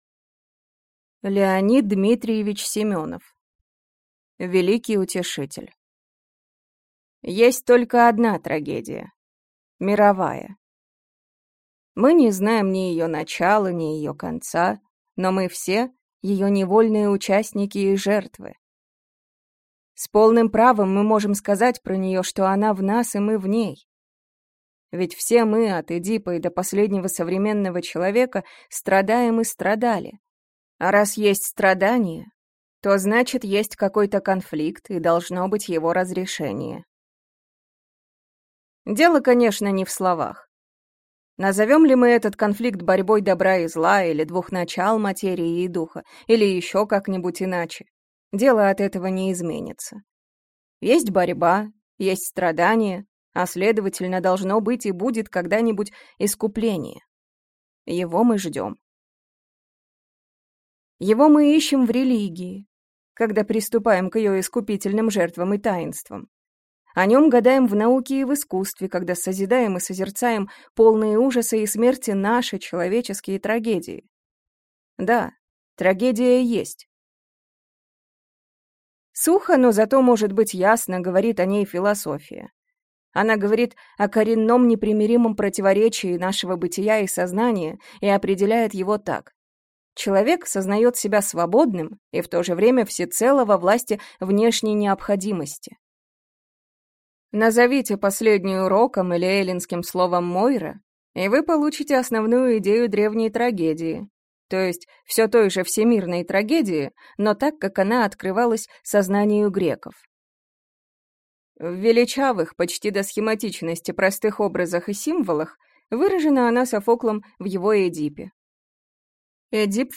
Аудиокнига Великий утешитель | Библиотека аудиокниг